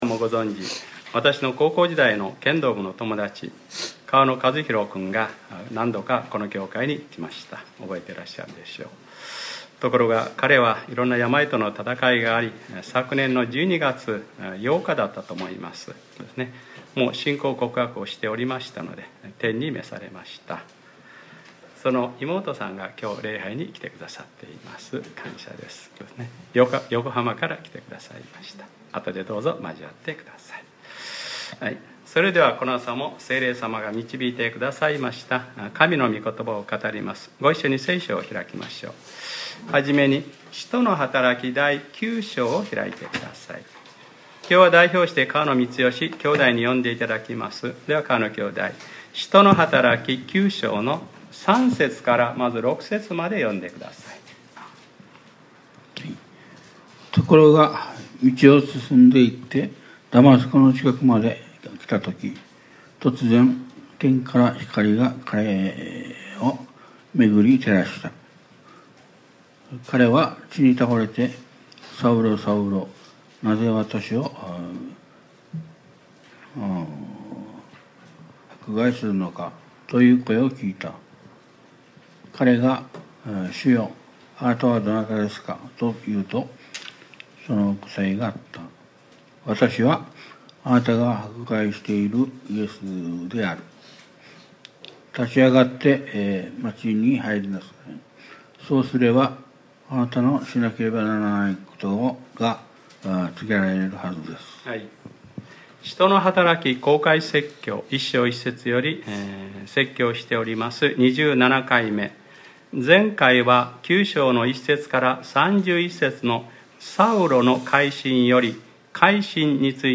2018年08月26日（日）礼拝説教『使徒ｰ27:わたしの選びの器』